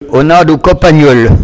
Enquête Arexcpo en Vendée
locutions vernaculaires
Catégorie Locution